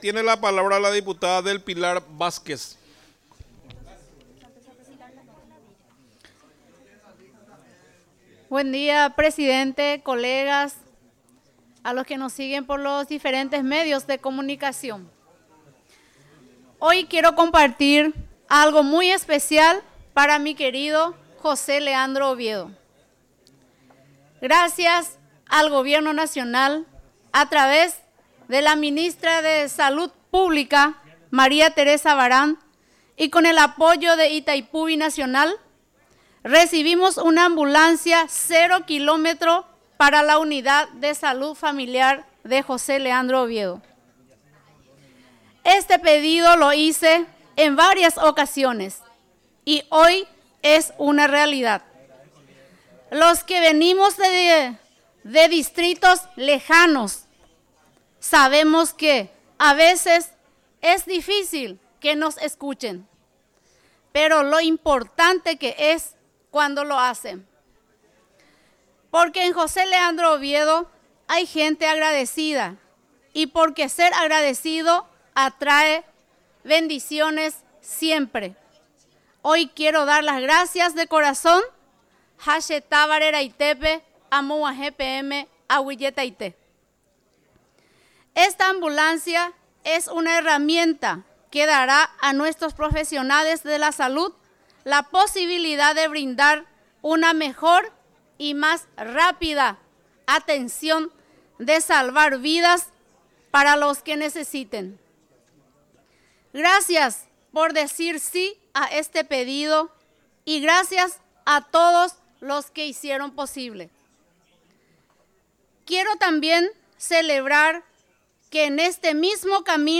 Sesión Extraordinaria, 26 de agosto de 2025
Exposiciones verbales y escritas
02 – Dip Raúl Benítez